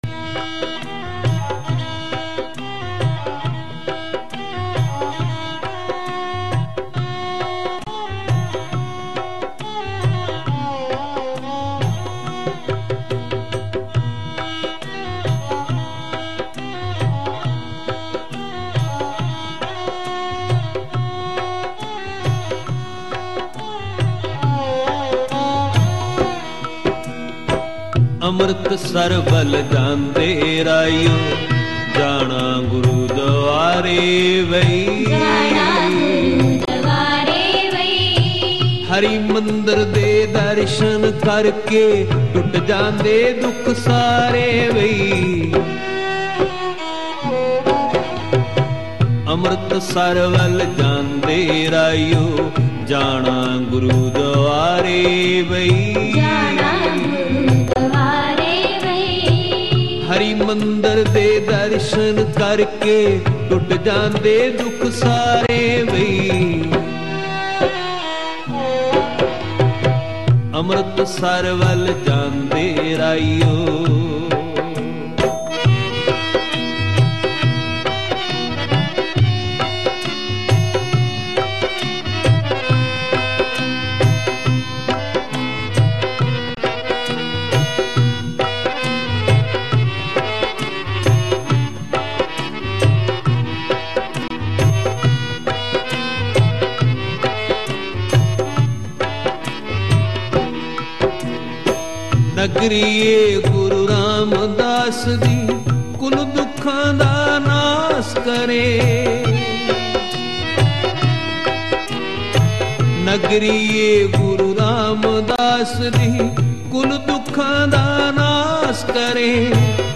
Kirtan with katha
Album: 01amritsar wal jande reheyo Genre: Gurmat Vichar